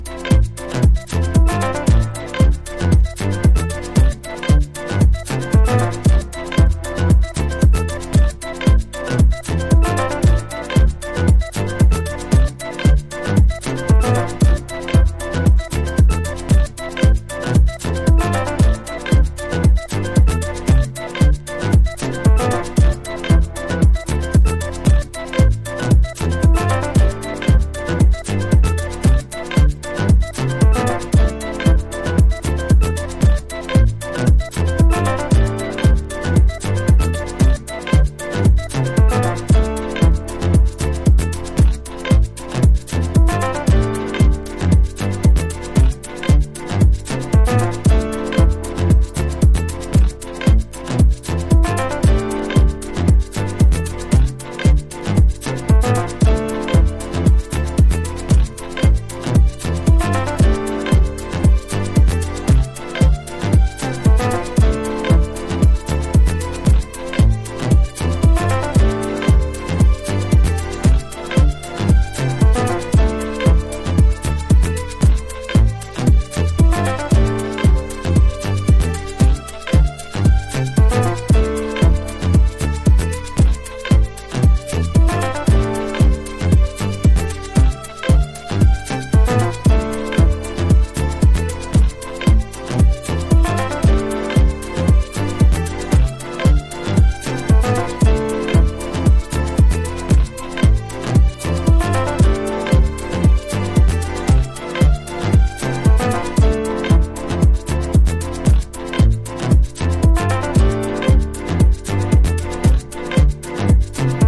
独創的なファンクネスを放つ、シンセワーク&ドラムプログラミングのブラック・メンタル・ディープハウス。
House / Techno